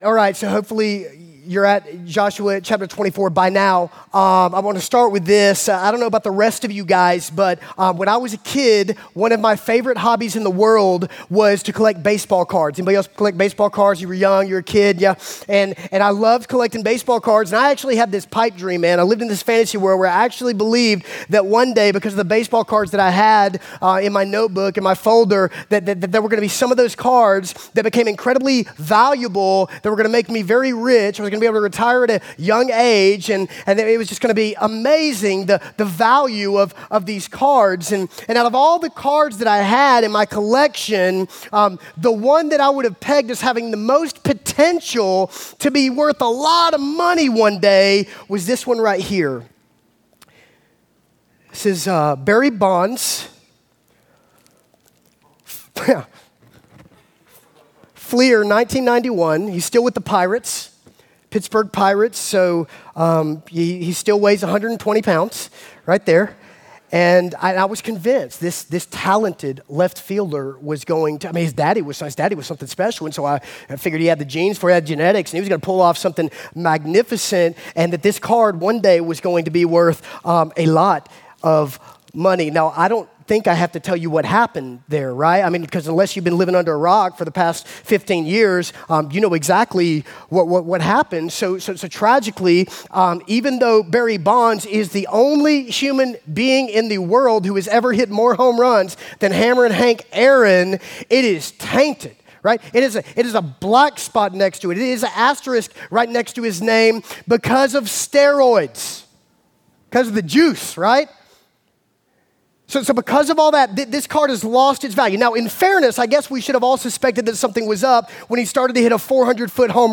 Father's Day 2014 Message